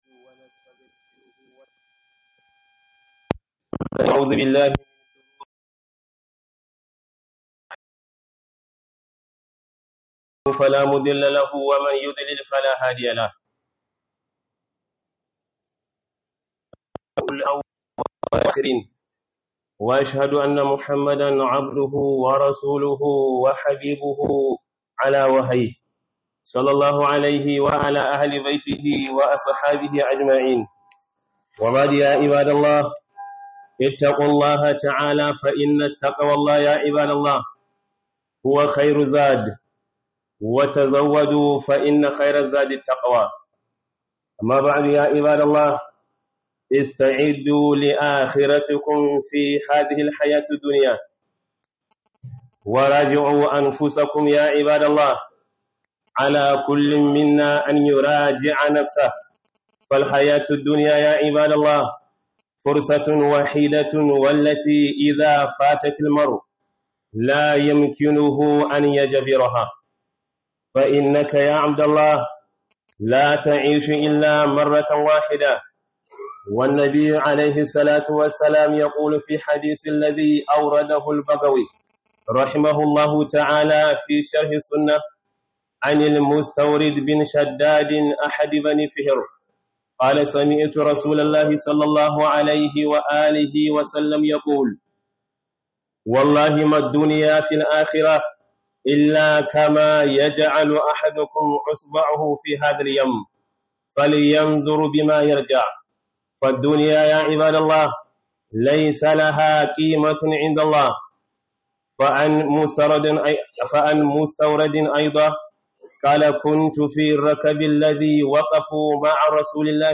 KHUDUBAR JUMA'A